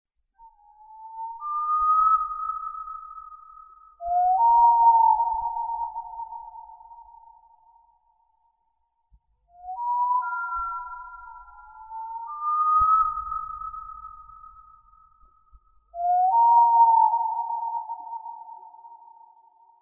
Сова